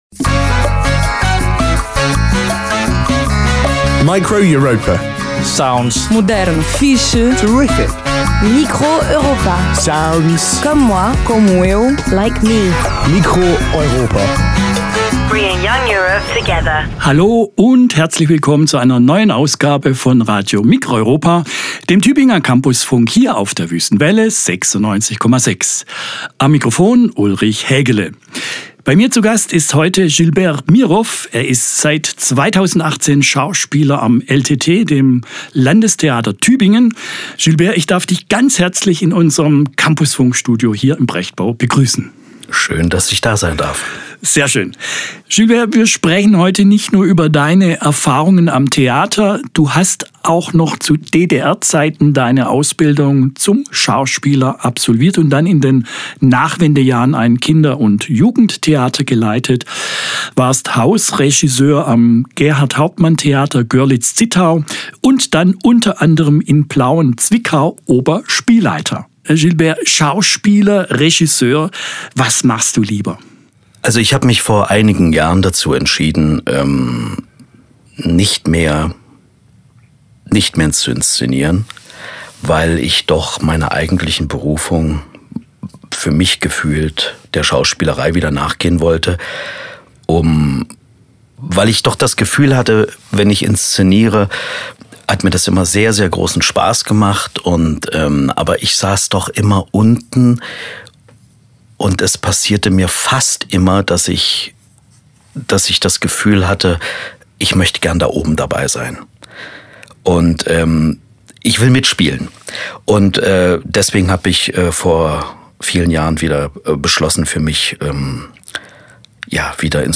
Studiogespräch
Form: Live-Aufzeichnung, geschnitten